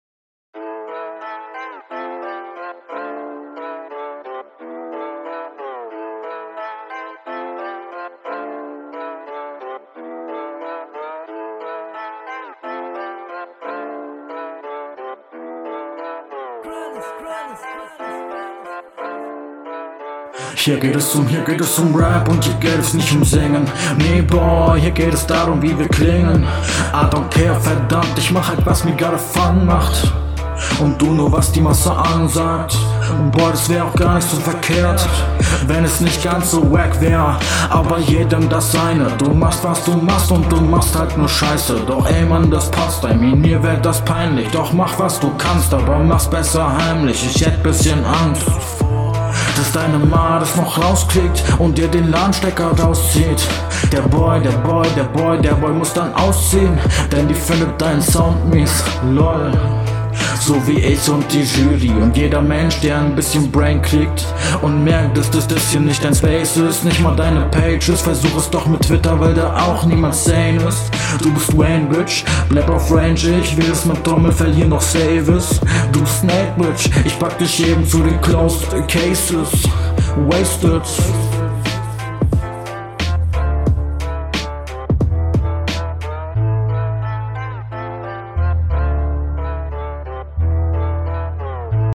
Hier kann man dich besser verstehen.